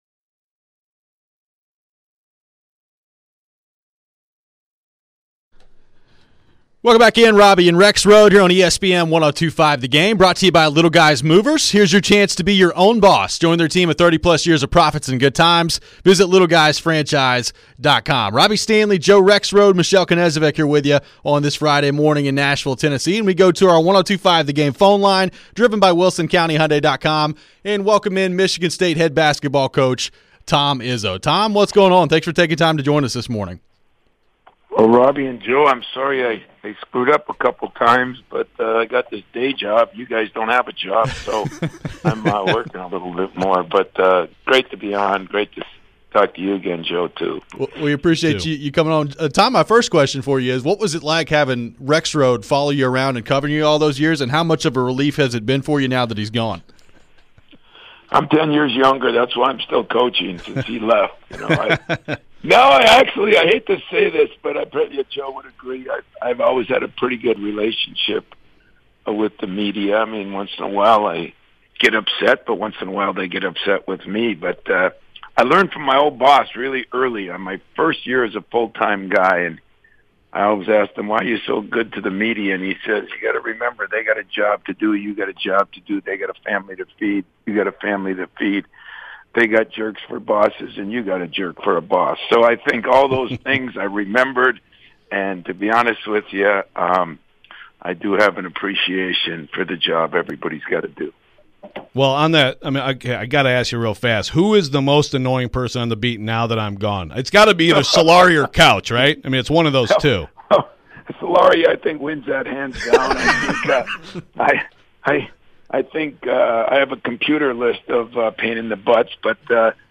Tom Izzo Interview